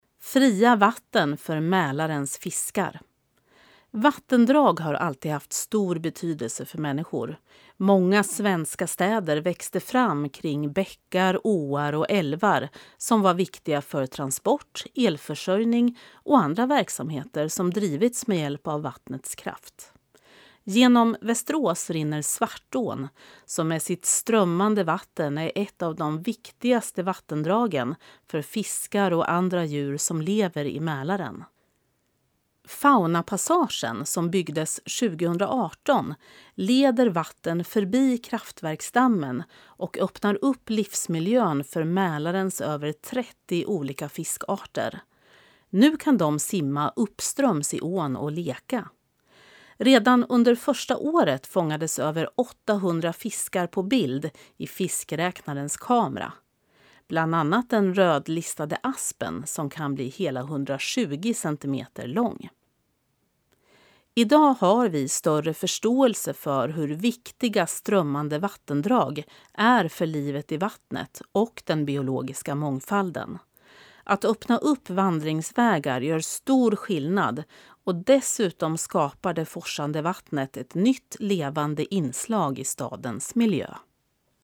Klicka här för att lyssna till texten, inläst av professionell uppläsare